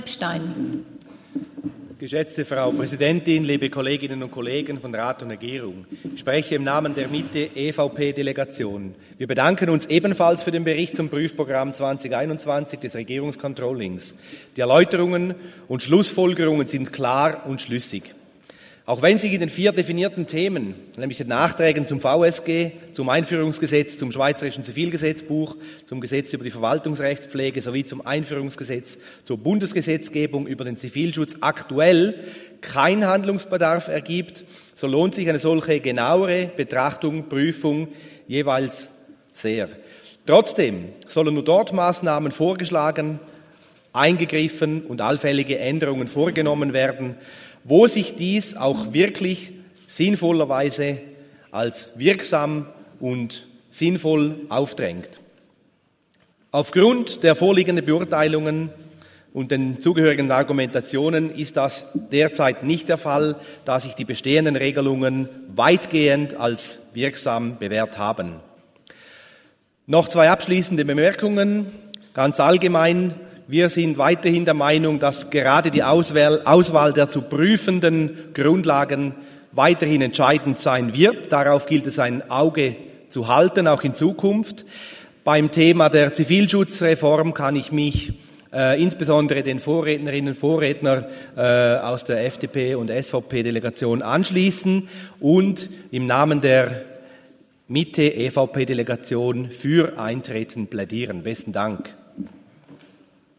Session des Kantonsrates vom 18. bis 20. September 2023, Herbstsession
19.9.2023Wortmeldung